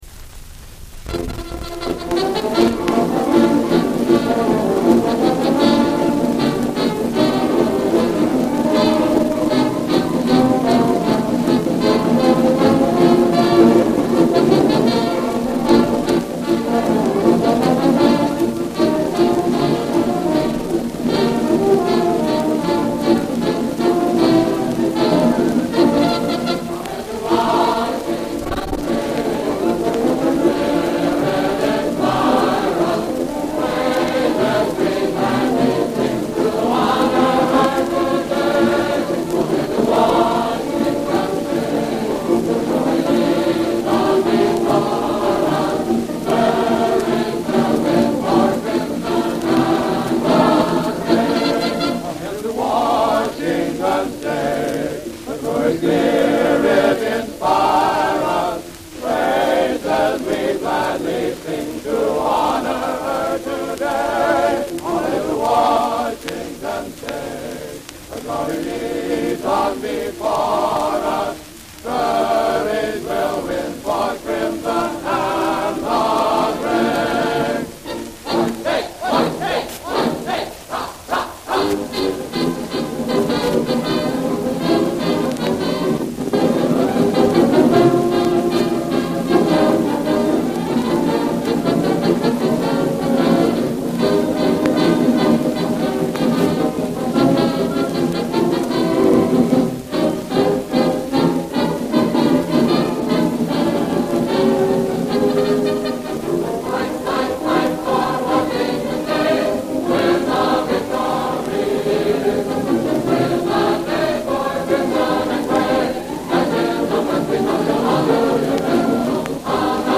Oldest known recording of the Washington State Fight Song
Recorded live for NBC Radio on May 15, 1934, at the Davenport Ballroom in Spokane performed by the Washington State College band and glee club.